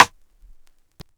Rim.wav